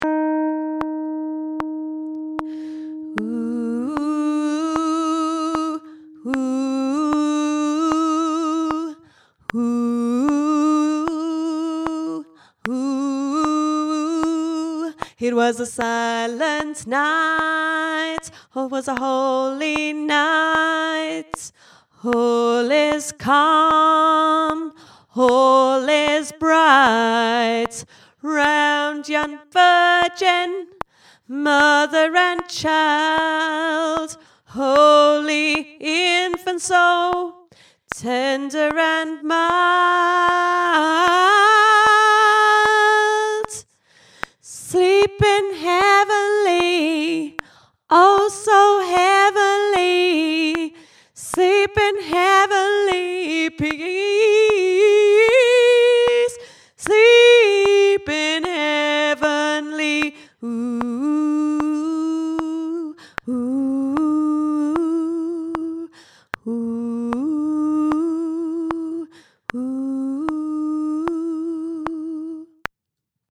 gospel-silent-night-alto
gospel-silent-night-alto.mp3